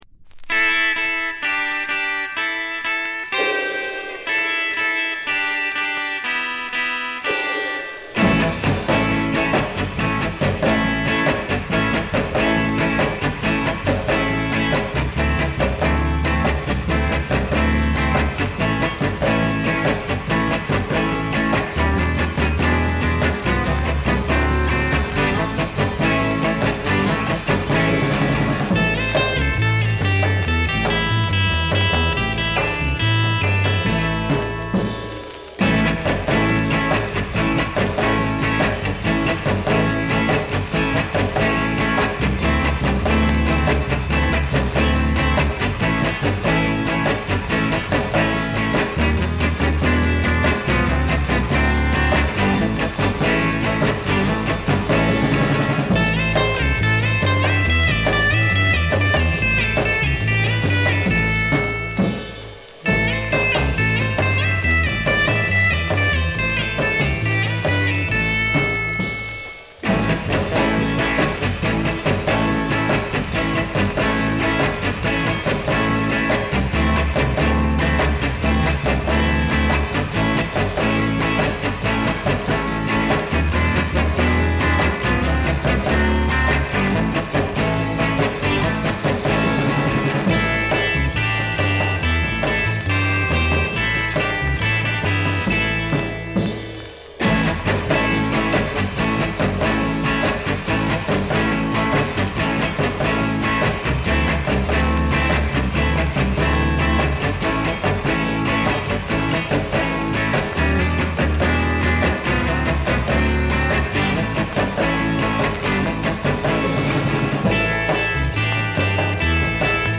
[Tittyshaker] [Exotica] [Comped] [NEW]